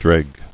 [dreg]